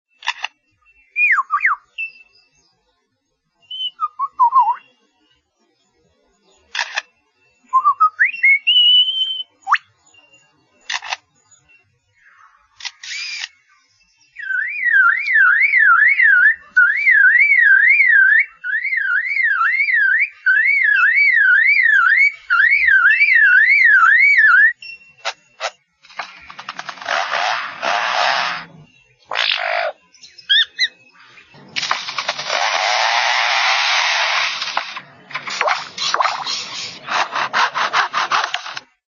Nature's master of sound-modulation, even machines -
The Superb Lyrebird from Australia.
Other birds, a camera-shutter, filmtransport of a camera, car-sirens, motorsaw and handsaw ..
Screenshot  waveform sirens-part   Amazing control of volume.
liervogel.ogg